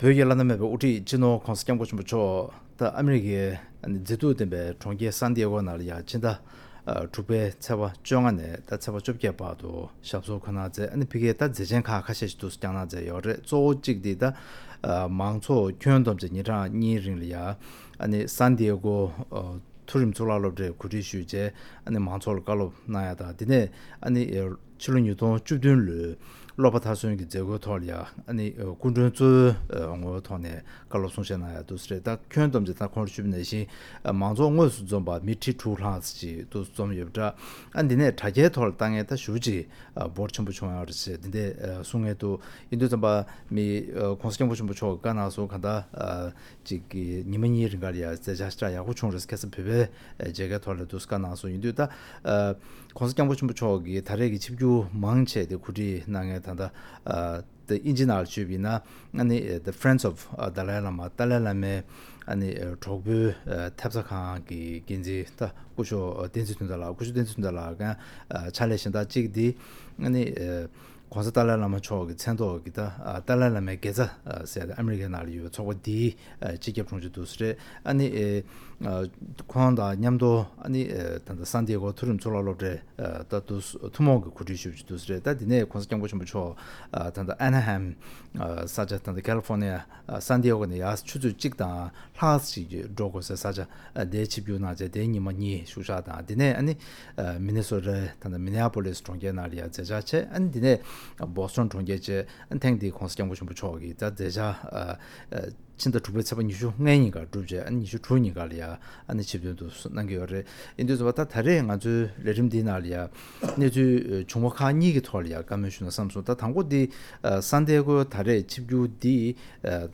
༧གོང་ས་༧སྐྱབས་མགོན་ཆེན་པོ་མཆོག་གི་སེན་ཌེ་སྒོ་ནང་གི་མཛད་འཕྲིན་ཐད་གླེང་མོལ།